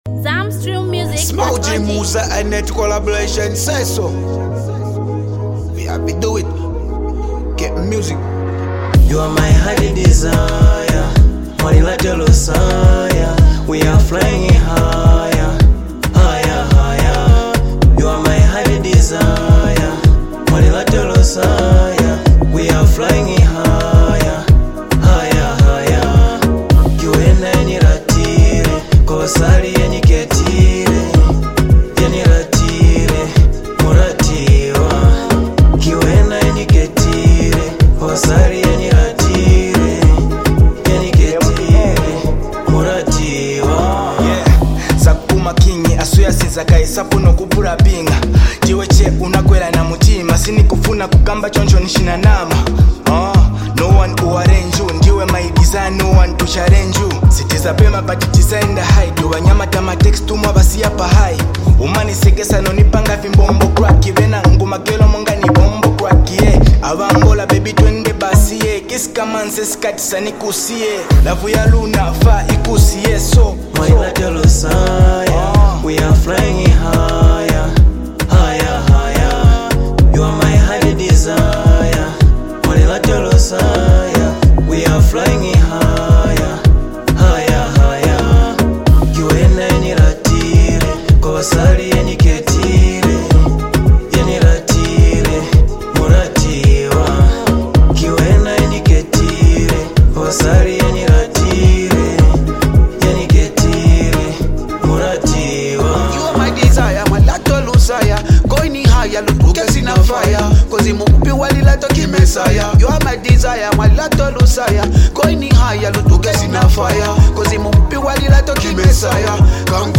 vibrant masterpiece track love song